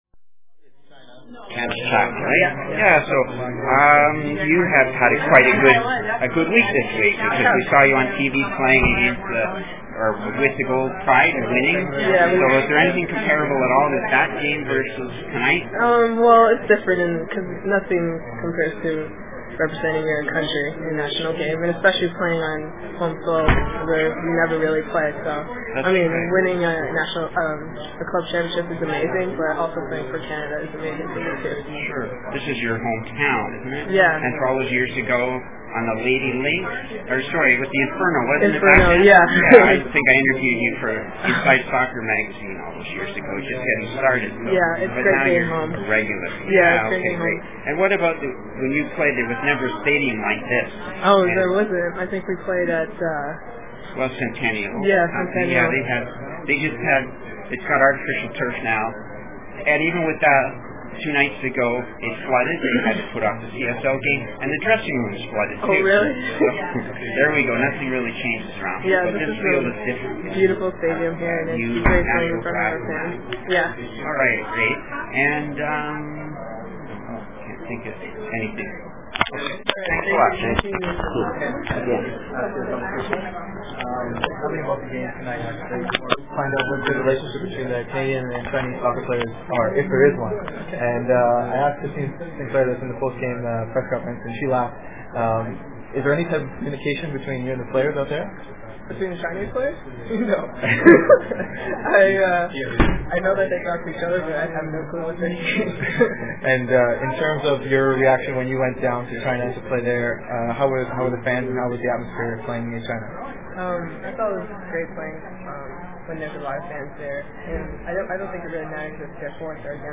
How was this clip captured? September 30, 2010 Canada vs China Women's friendly (post game press conference) held at BMO Field in Toronto at 9:30pm.